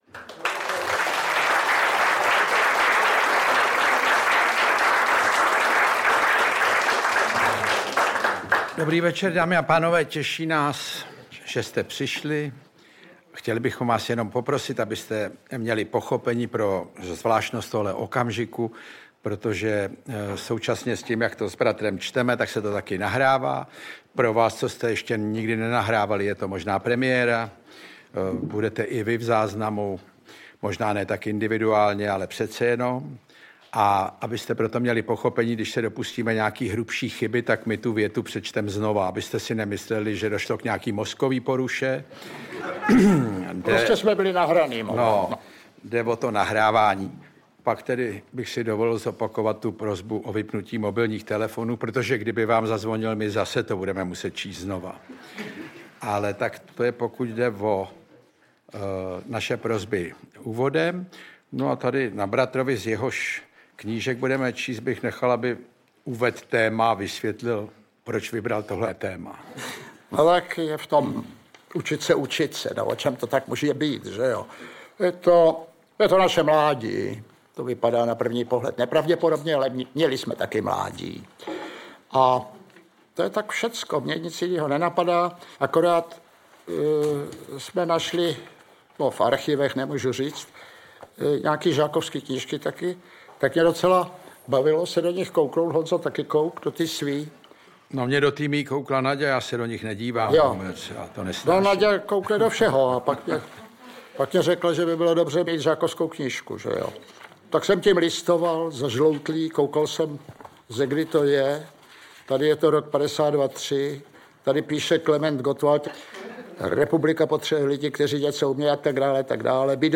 Snahou bratrů Krausových je - doložit toto moudré rčení několika osobními důkazy. Originální a autentické autorské čtení z knih Ivana Krause doplňuje řada vtipných glos a poznámek. Záznam představení z pražské Violy